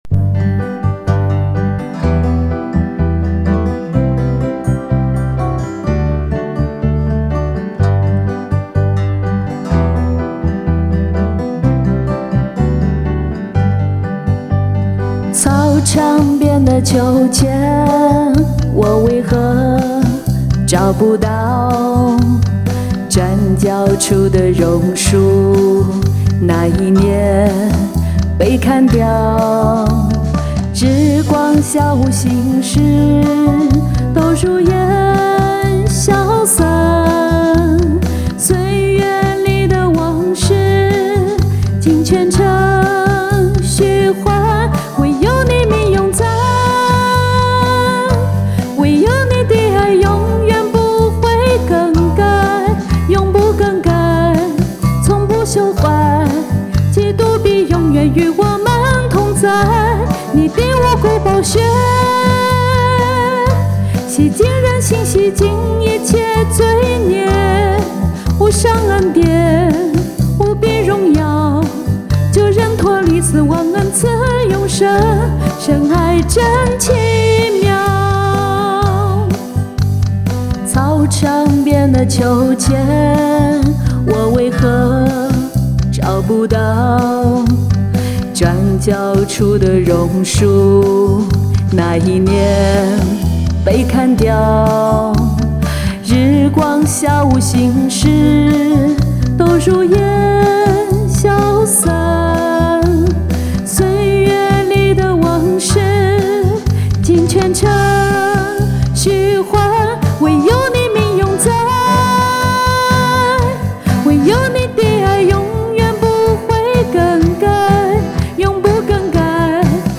献唱/赞美新歌《你的爱永不更改》